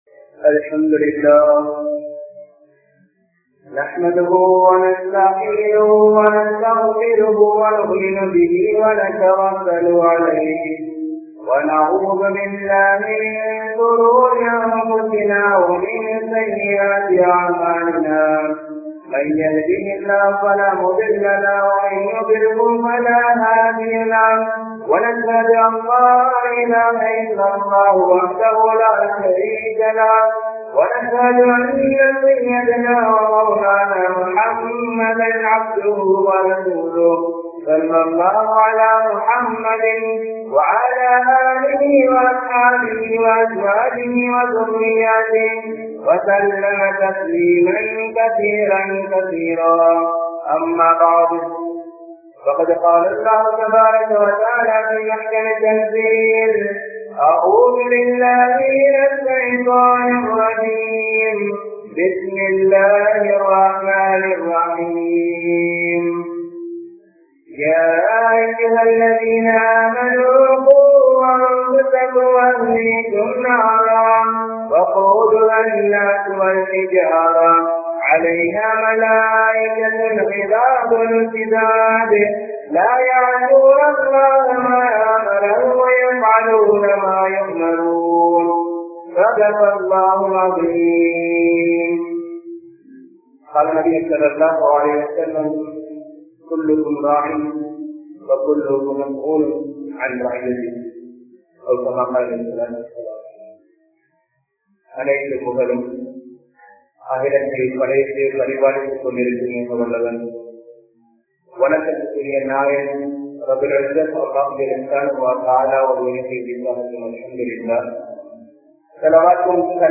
Media Vaal Naraham Sellum Petroarhal (மீடியாவால் நரகம் செல்லும் பெற்றோர்கள்) | Audio Bayans | All Ceylon Muslim Youth Community | Addalaichenai